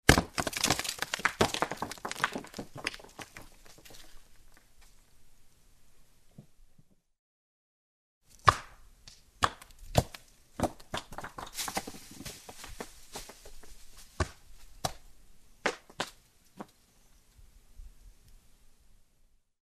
На этой странице собраны звуки камнепада — от легкого шелеста скатывающихся камешков до грохота крупных обвалов.
Камень катится вниз по склону